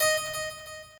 harp6.ogg